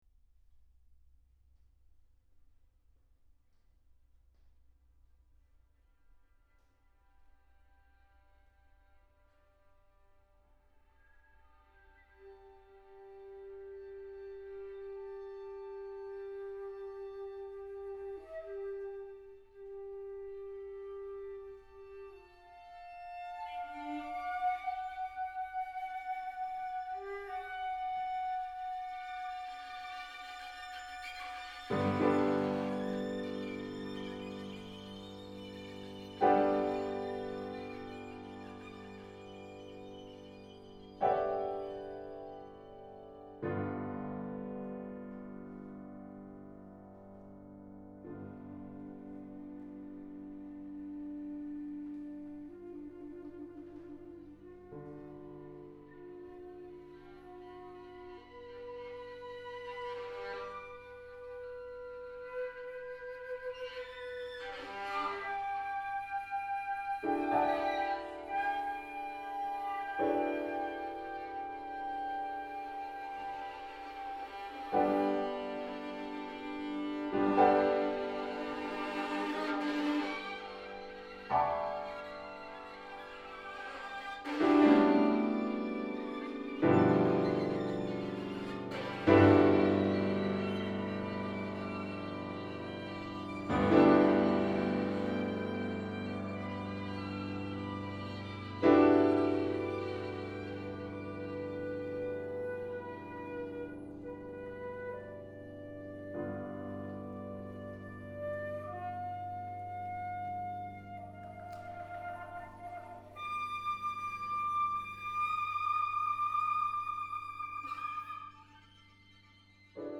modern music making . . .